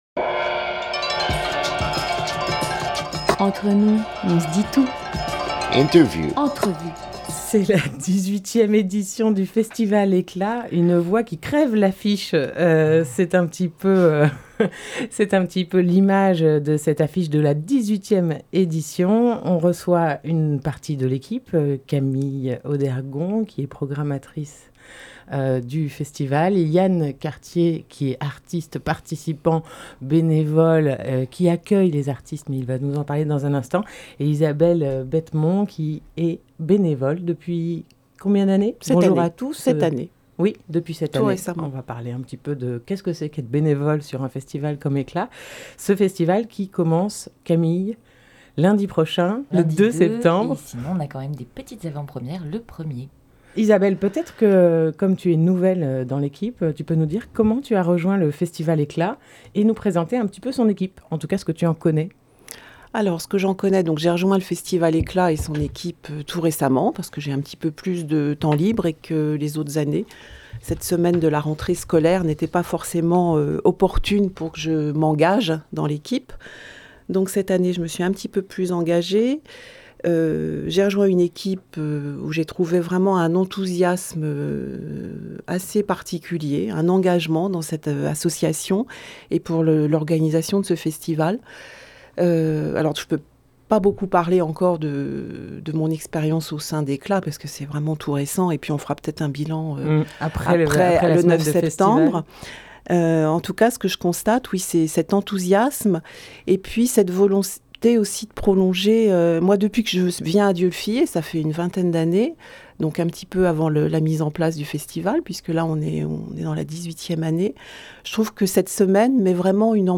2 septembre 2019 15:45 | Interview